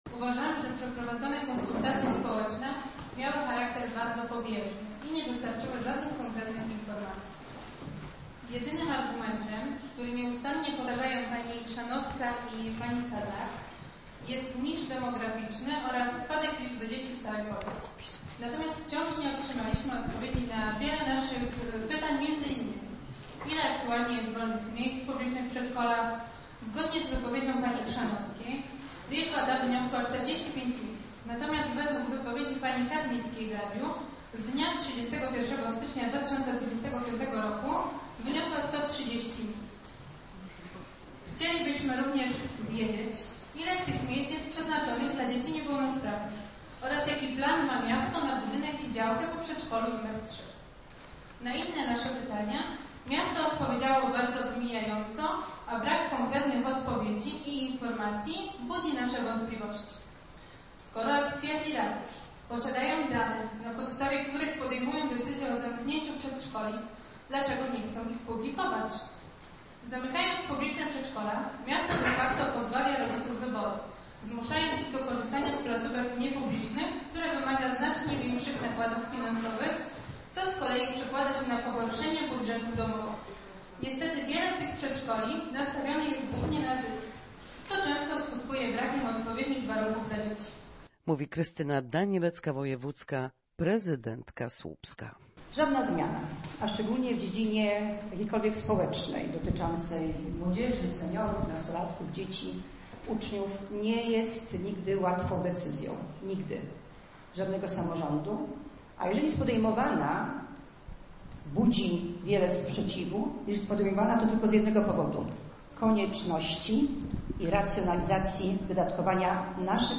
Kilkadziesiąt osób przyszło na wczorajsze spotkanie z radnymi i władzami Słupska, aby przedstawić swoje postulaty i racje dotyczące likwidacji jednego z miejskich przedszkoli. Dyskusja trwała dwie godziny.